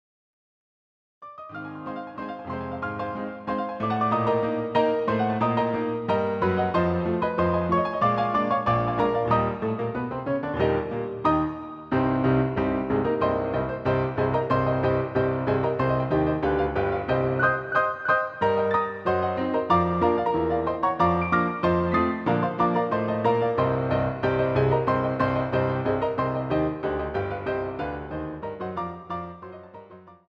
using the stereo sampled sound of a Yamaha Grand Piano.